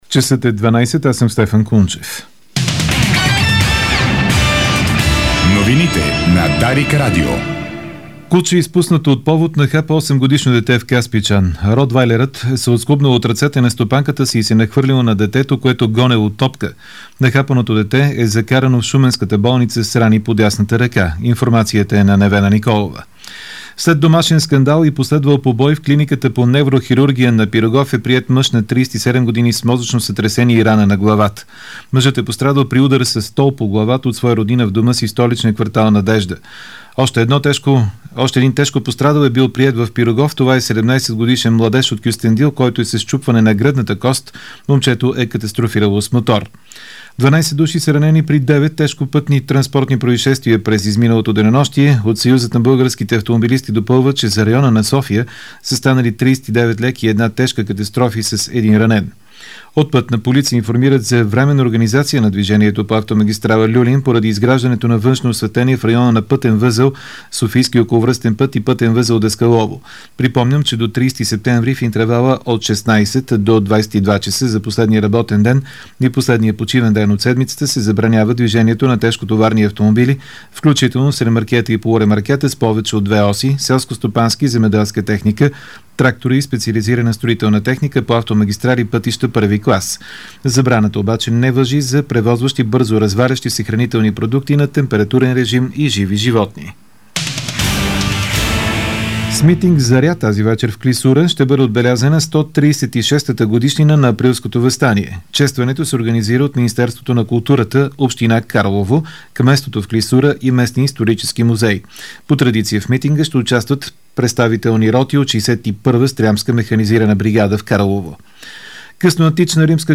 Обедна информационна емисия - 30.04.2012